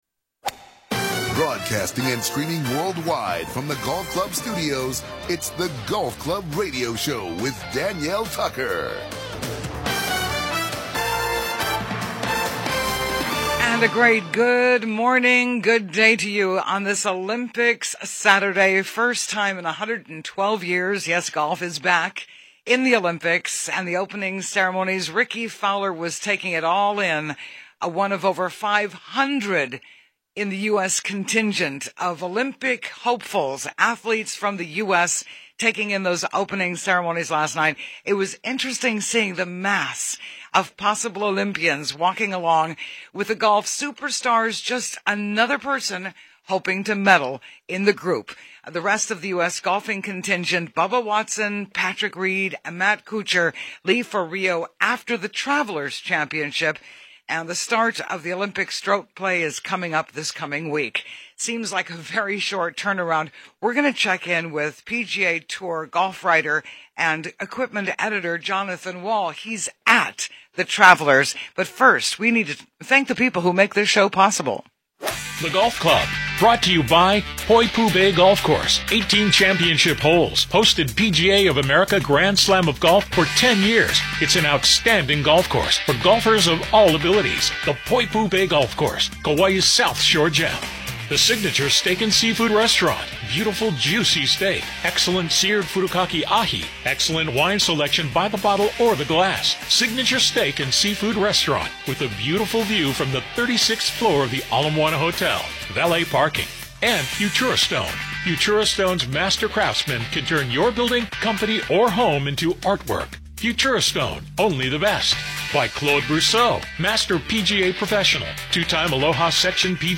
SATURDAY MORNINGS: 7:00 AM - 8:30 AM HST MAUI OAHU KAUAI HILO KONI FM 104.7 KGU FM 99.5 KTOH FM 99.9 KPUA AM 670